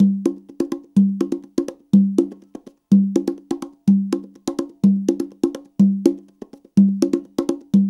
CONGA BEAT42.wav